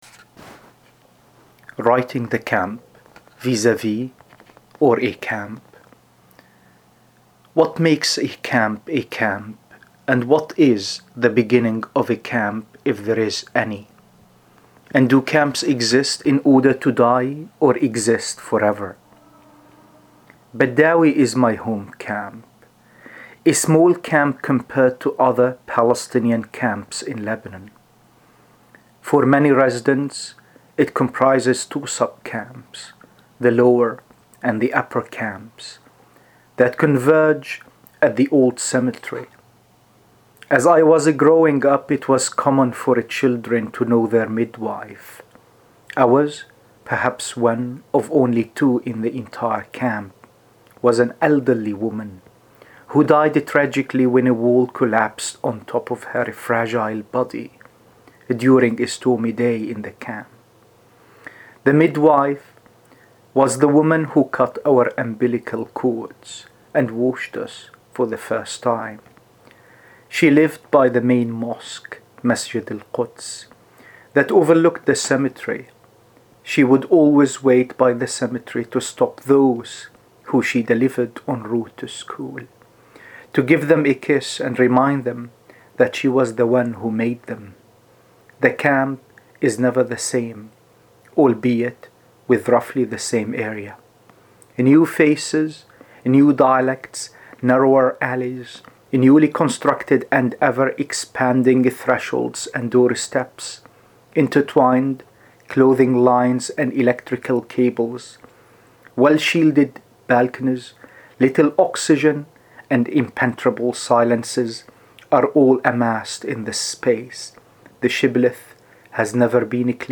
read his poem here: